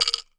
wood_block_rattle_movement_03.wav